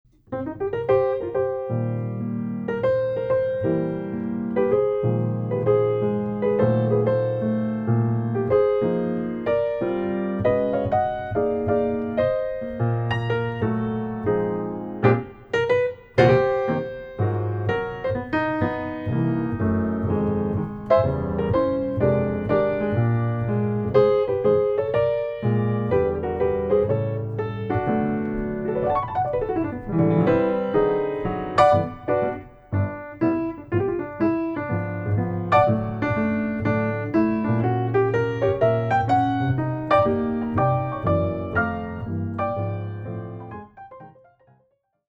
Recorded on July 13.2025 at Studio Happiness